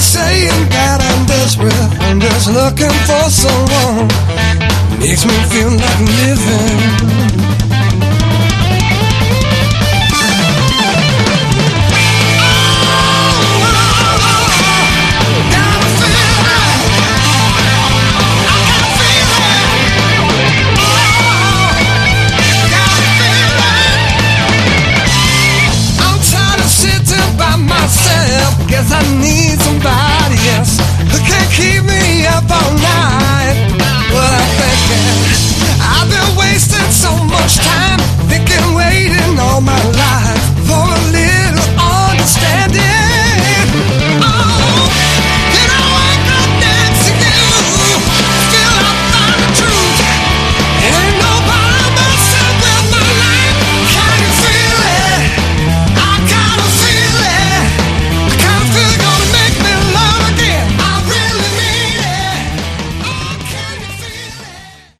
Category: Hard Rock
vocals, guitar, mandolin
keyboards
bass
drums, percussion
additional backing vocals